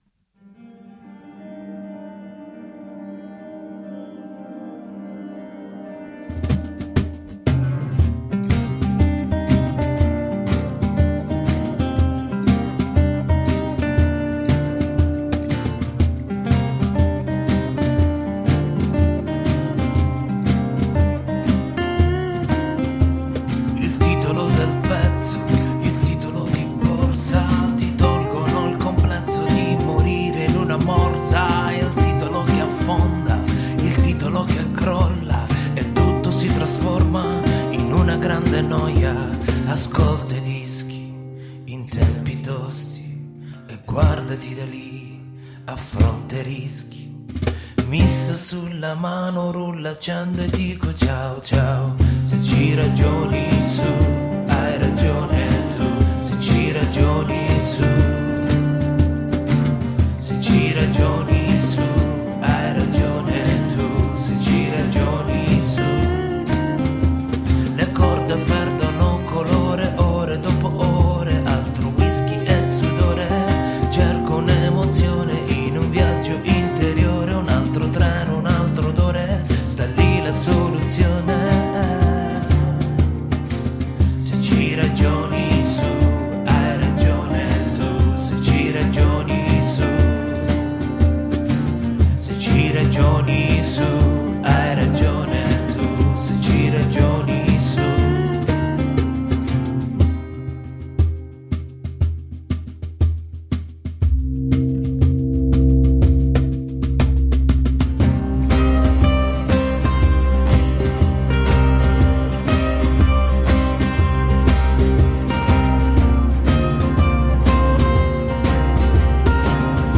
Relaxed pop and reggae music made in italy.